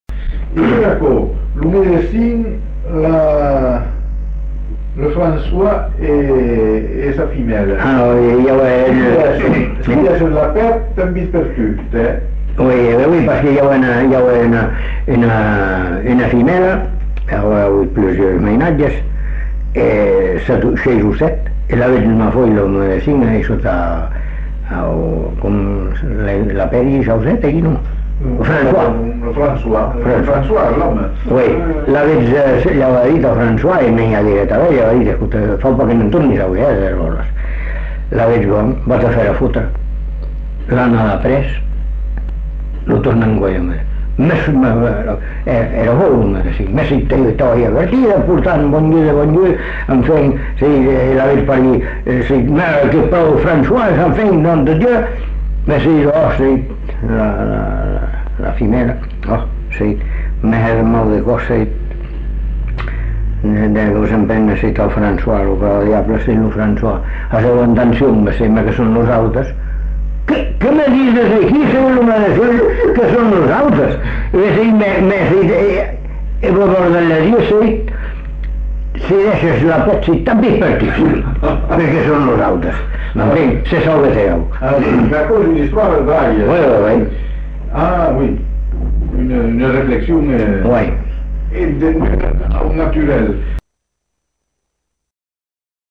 Aire culturelle : Bazadais
Genre : conte-légende-récit
Type de voix : voix d'homme
Production du son : parlé
Classification : récit anecdotique